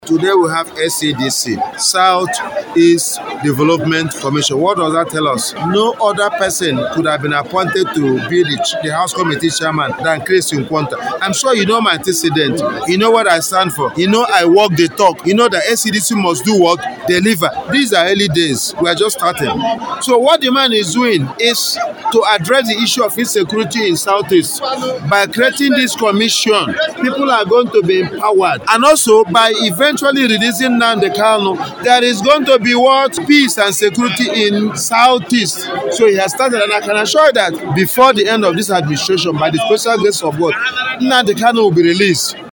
Hon. Nkwonta who made this known during an Agenda Setting Meeting of APC members in Abia South Senatorial Zone comprising of Aba North, Aba South, Ugwunagbo, Ukwa East, Ukwa West and Obingwa, which held at his country home Akwete, maintained that APC in Abia South must galvanize to win elections across board in 2027 stressing that this can only be achieved through membership drive and party dominance as he called on them to bring in more committed members into the party.